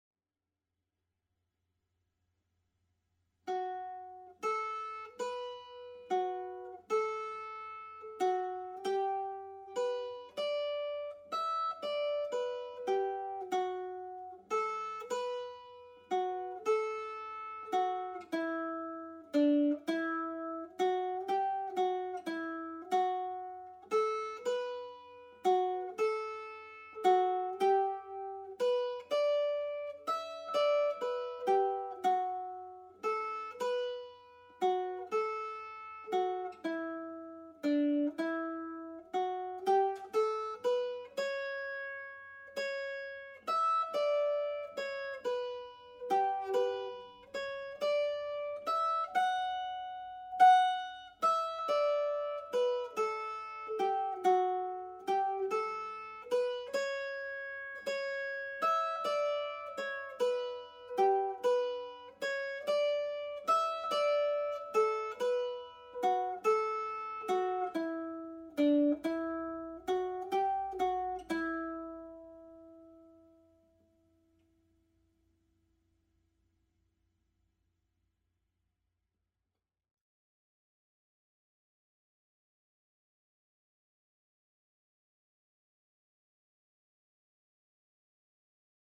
Slip Jig (D Major)
Listen to the tune played slowly
The-Snowny-Path_slow.mp3